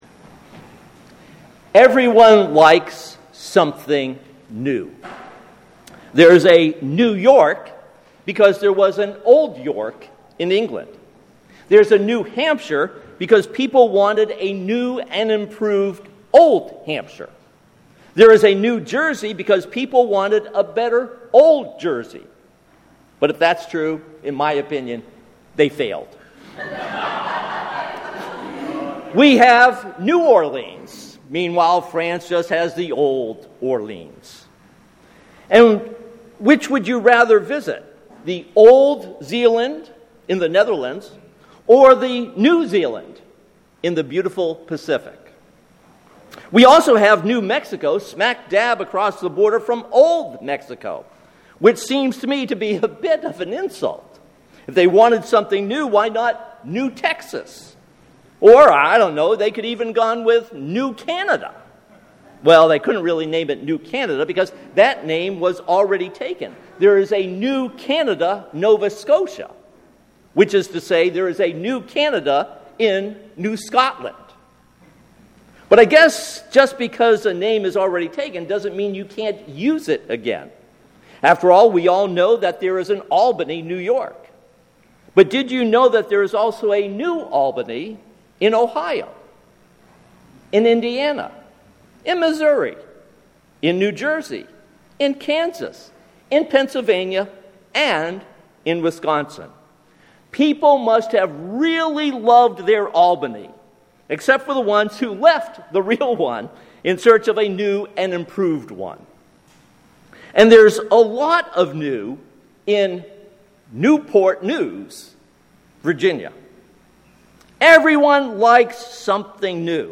This sermon is based on Ephesians 4:20-24.